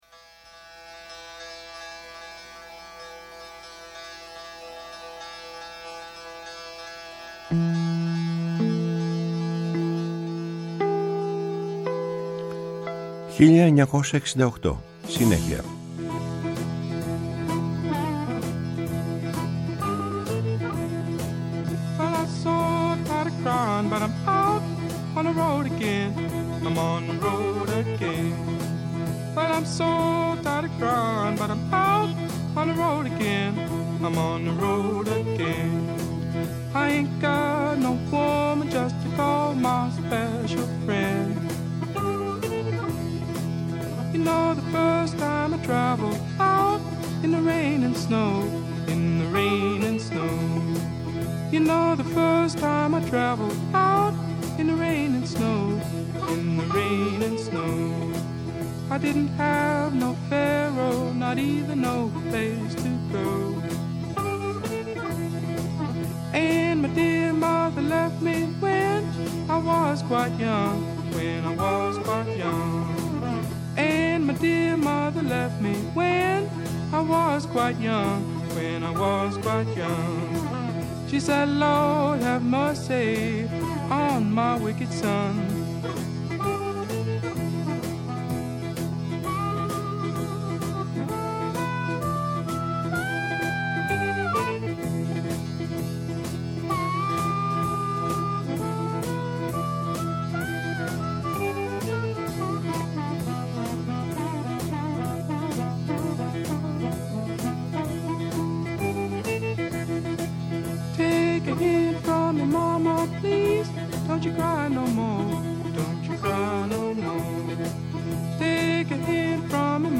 Η μακροβιότερη εκπομπή στο Ελληνικό Ραδιόφωνο!
ΜΟΥΣΙΚΗ